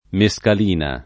mescalina [ me S kal & na ] s. f. (chim.)